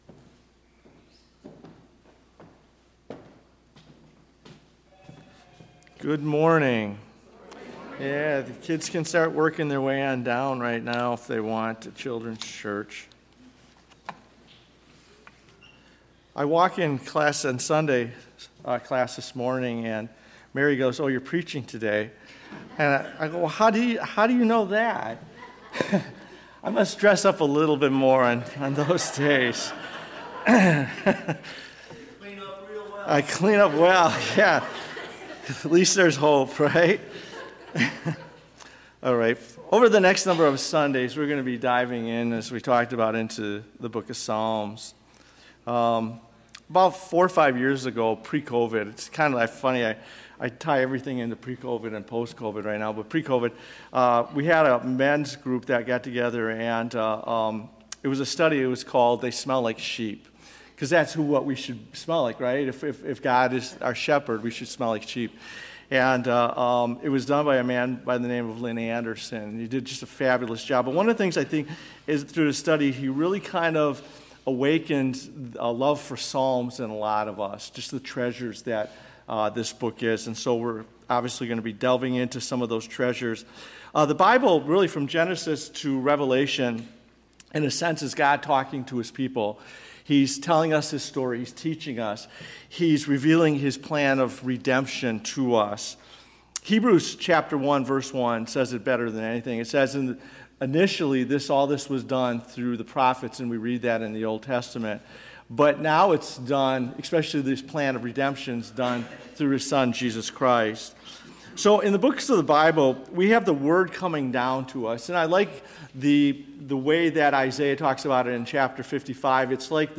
Sermon-Audio-January-7-2024.mp3